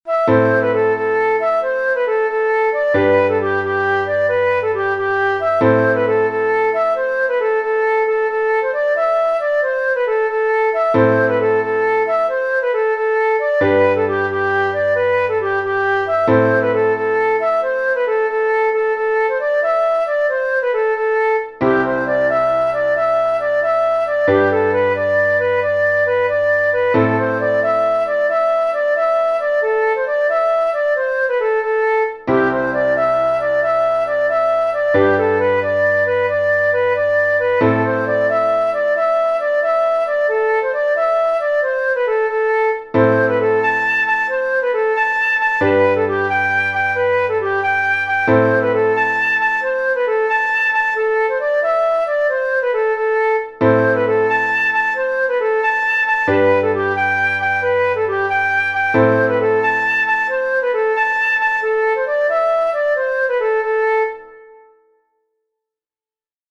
Slow Sessions Irlandaises 22